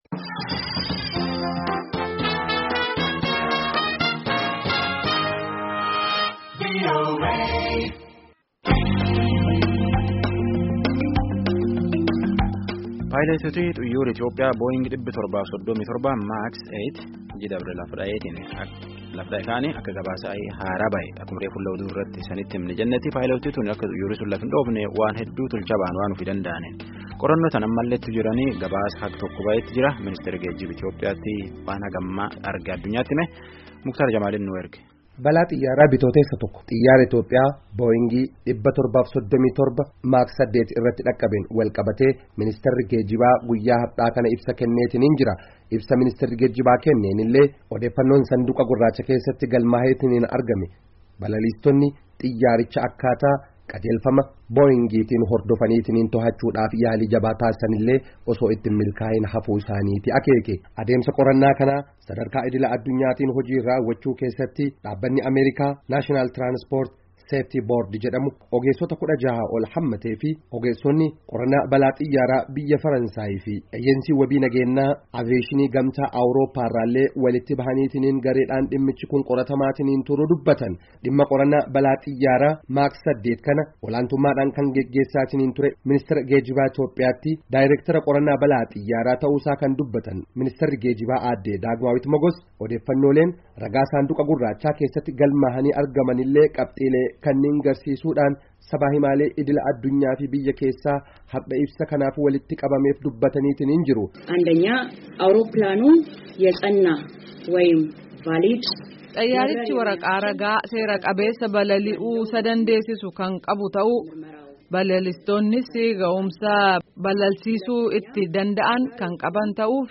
FINFINNEE —